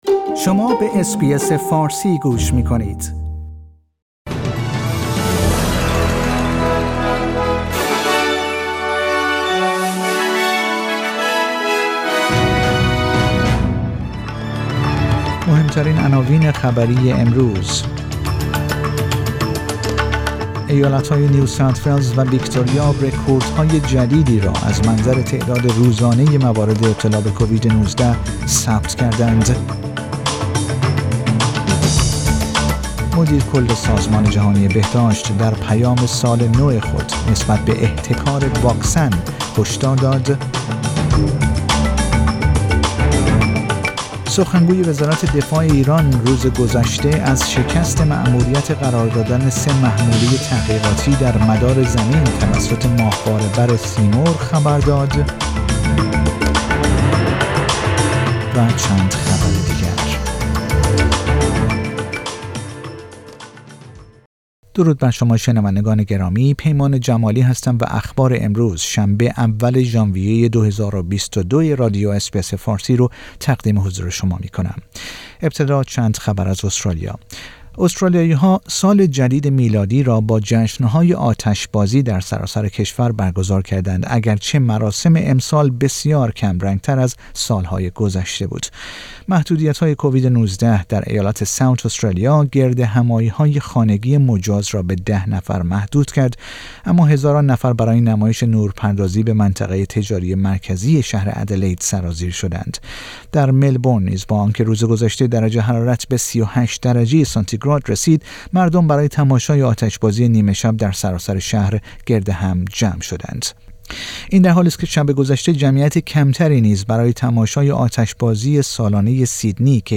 پادکست خبری آخر هفته اس بی اس فارسی